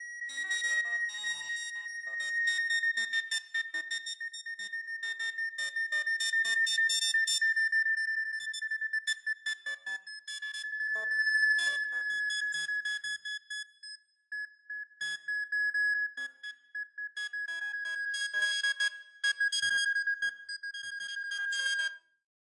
描述：具有强烈调制速度的重复电子声音的序列，包括AM副作用。
Tag: 电子 滤波器 谐波 振荡器 序列 载体